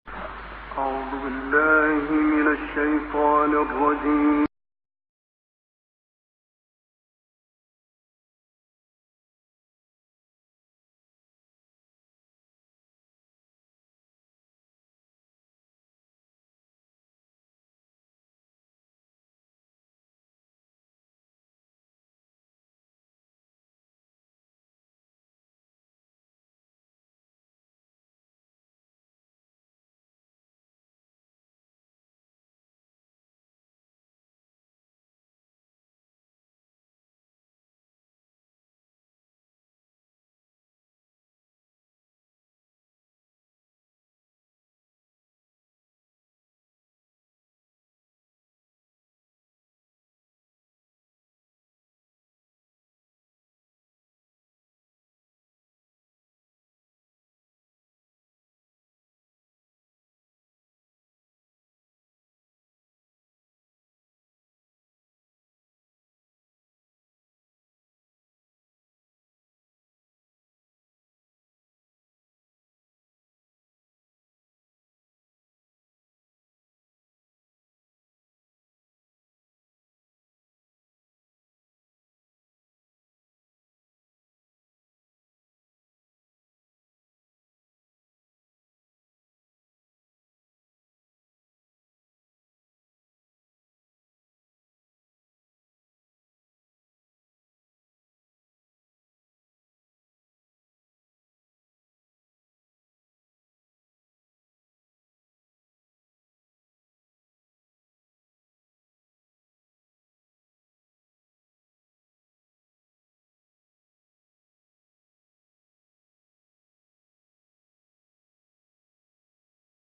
enregistrement en mosquée
Live recordings Surahs Saba’ & Al-Infi t âr Style: tajwîd.